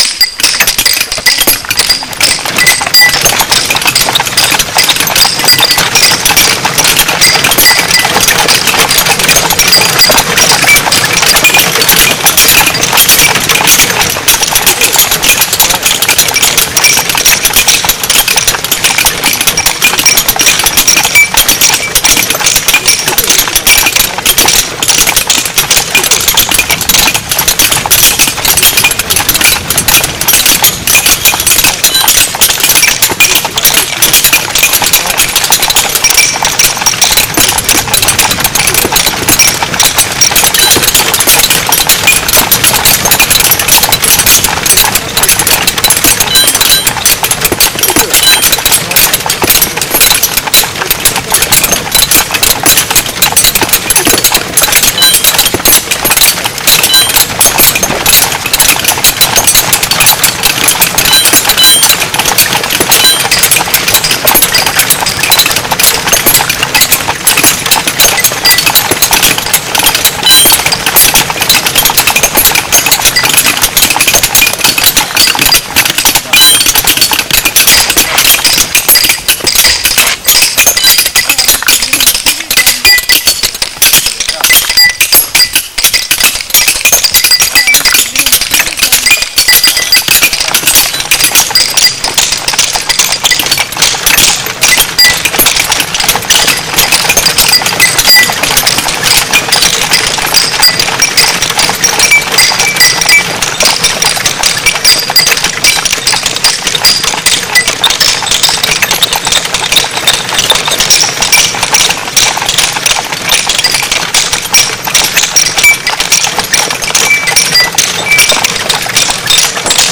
We have put together this audio clip to provide a basic idea of how noisy it may have been chiselling way within the confines of the cave complex with the sound bouncing round the chambers.
Compiled_mining_sounds.mp3